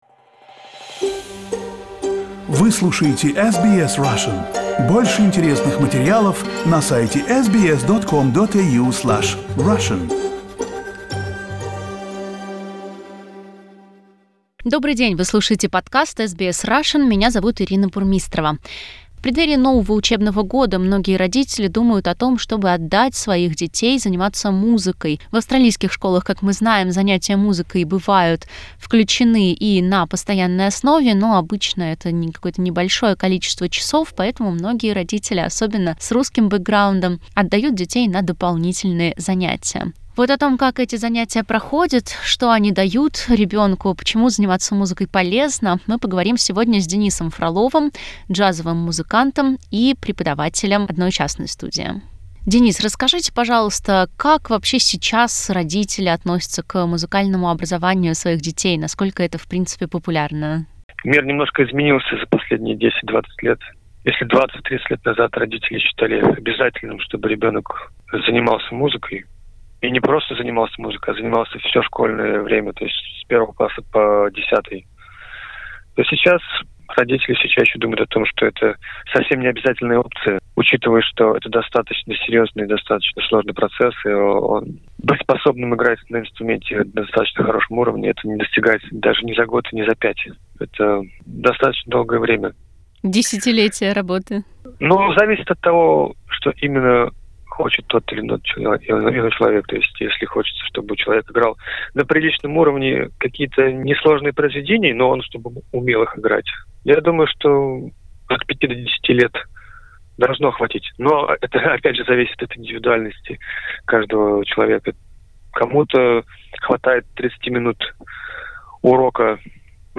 Interview with a jazz musician and teacher